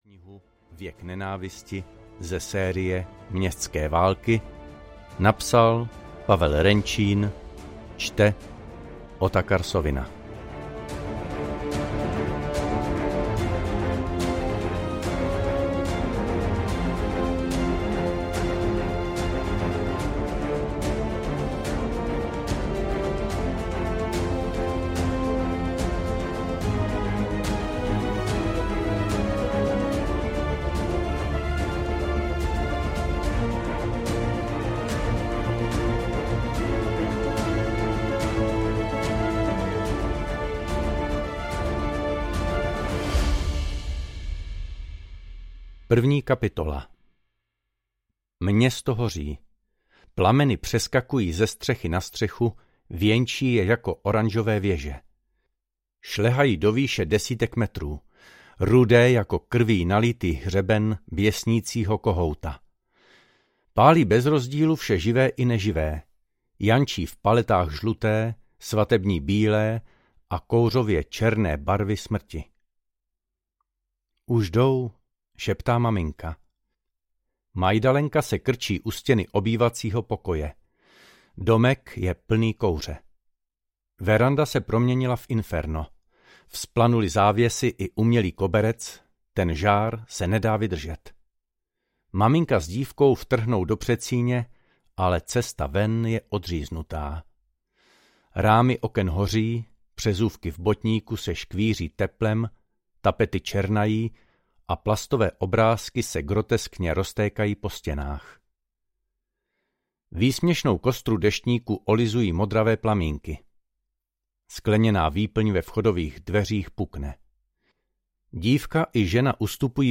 Věk nenávisti audiokniha
Ukázka z knihy